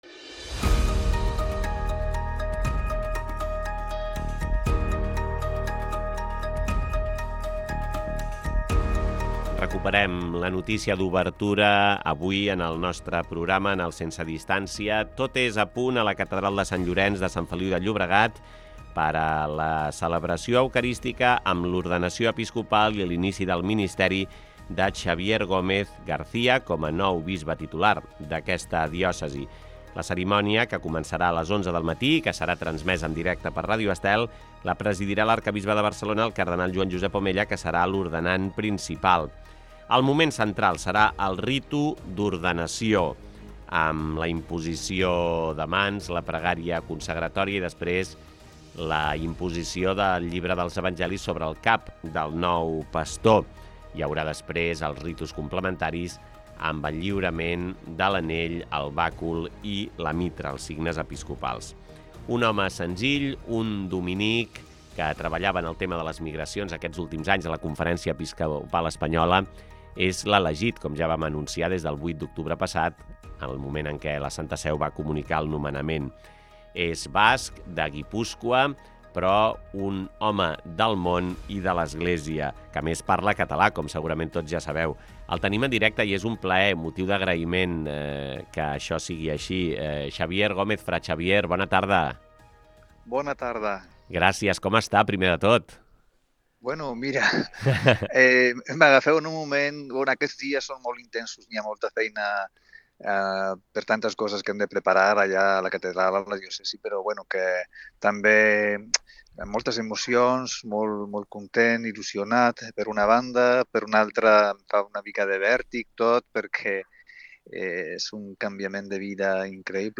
Entrevista Xabier Gómez, nou bisbe de Sant Feliu de Llobregat